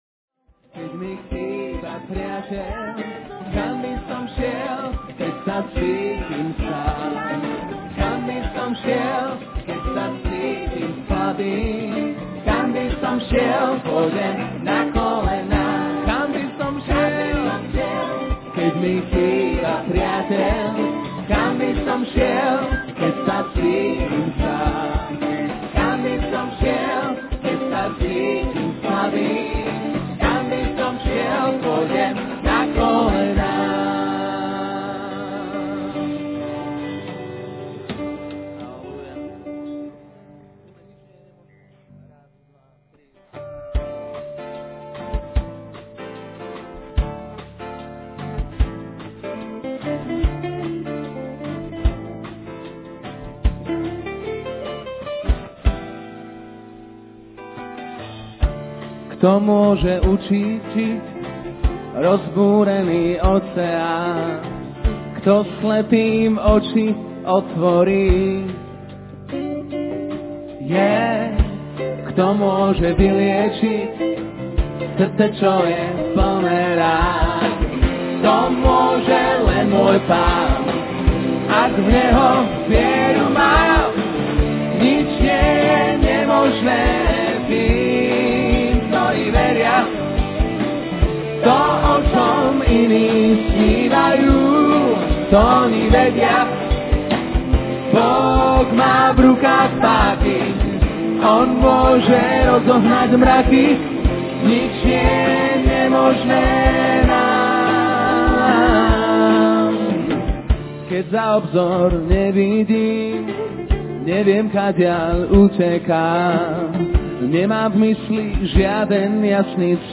In this sermon, the speaker emphasizes the importance of the cross and the message of Jesus Christ.